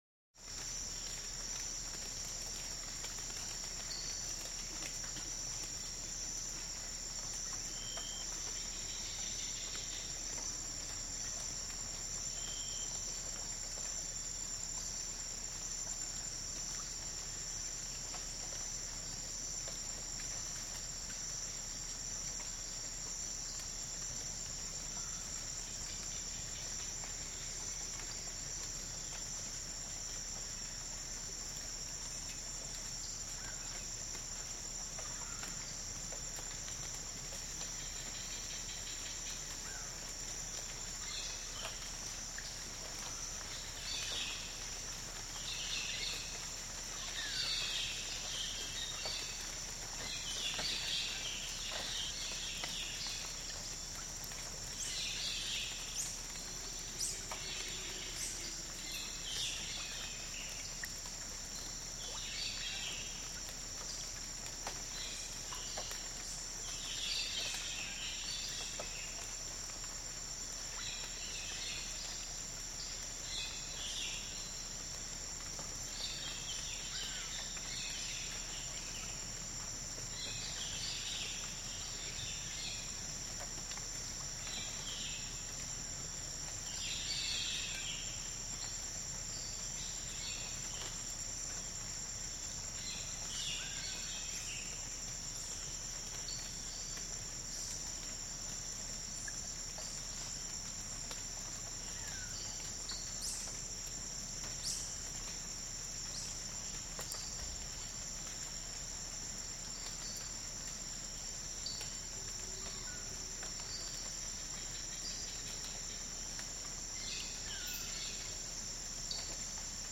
Шепот дождя в тропическом лесу